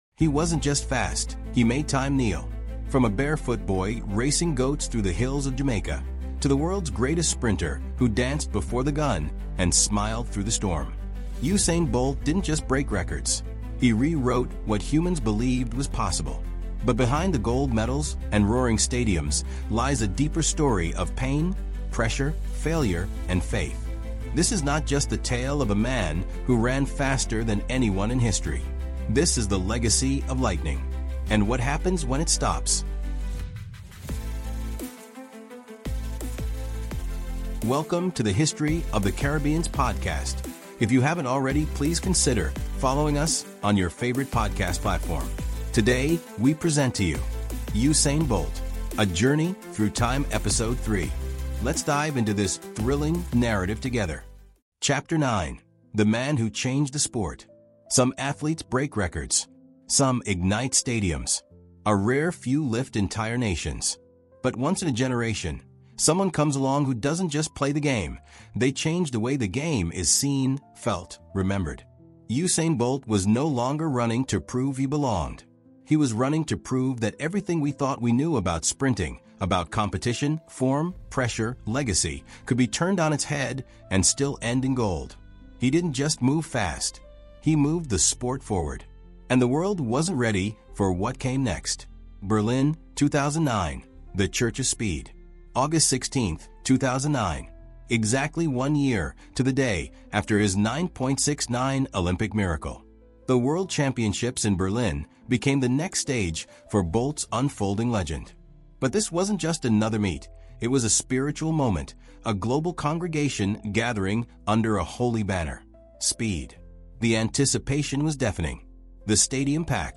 Jamaica, history experts, caribbean history fuel this high‑velocity episode tracing Usain Bolt’s journey from rural tracks to Olympic lightning. Packed with cultural renaissance insights, cinematic narration, and raw emotion, it’s a sprint through joy, doubt, and destiny.